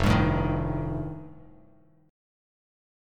F9 Chord
Listen to F9 strummed